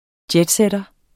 Udtale [ ˈdjεdˌsεdʌ ]